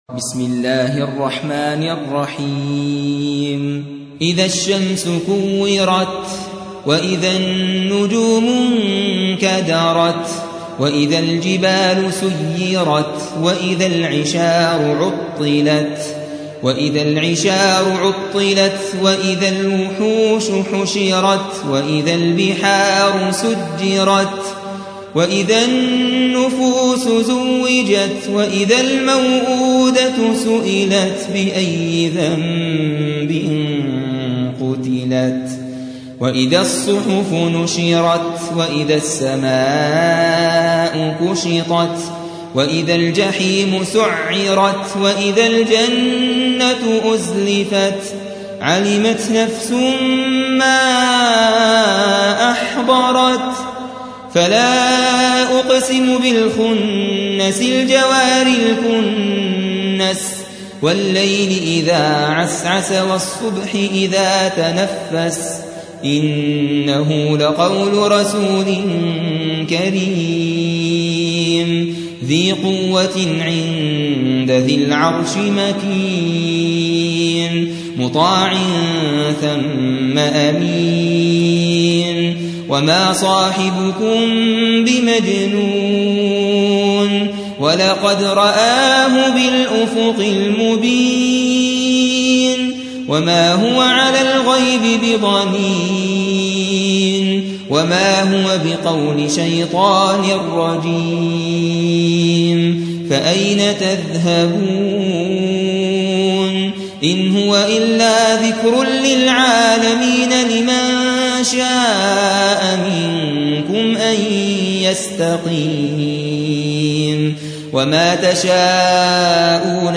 81. سورة التكوير / القارئ